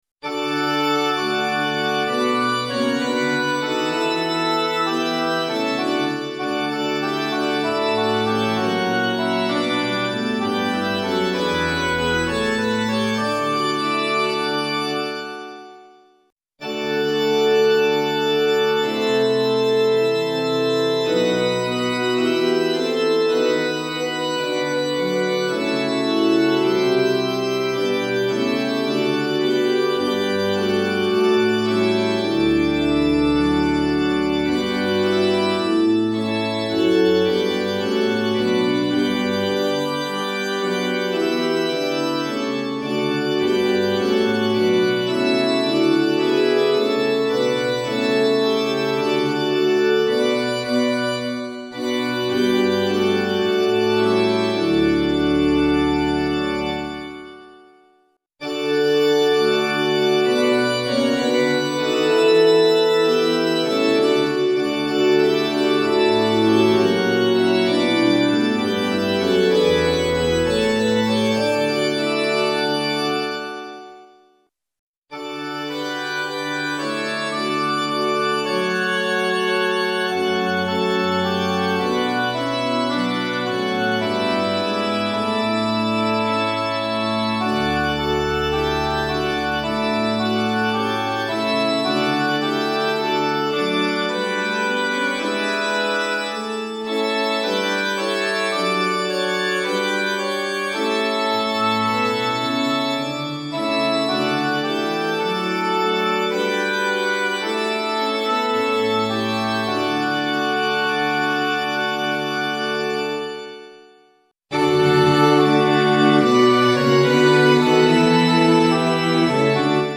混声四部合唱+器楽 Four-part mixed chorus with Instruments
Sample Sound ：参考音源 - 様々な音色の組み合わせによる
DL Ob+Org Rcd+Org Rcd+Org Ob+Org Ob+Org+Org